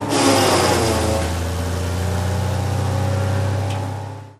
Mulcher Rips Wood 4x